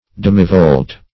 Search Result for " demivolt" : The Collaborative International Dictionary of English v.0.48: Demivolt \Dem"i*volt`\, n. [Cf. F. demi- volte.]
demivolt.mp3